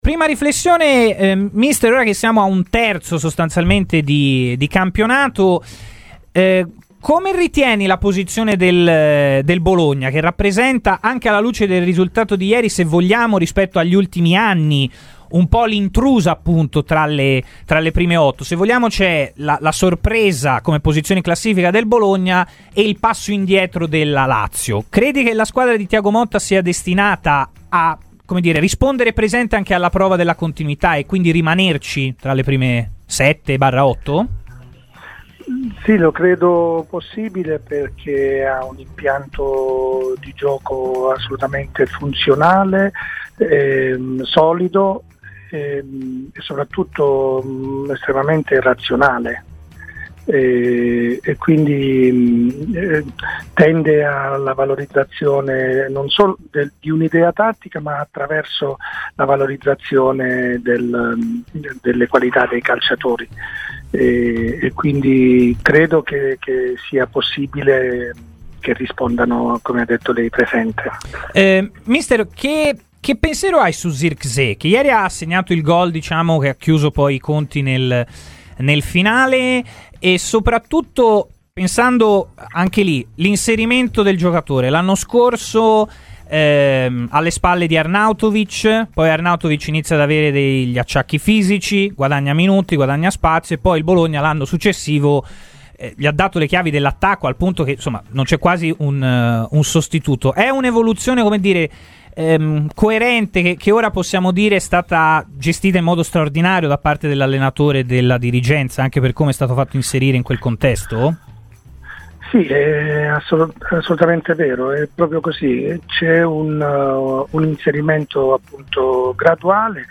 Ascolta il resto dell'intervista attraverso il nostro podcast!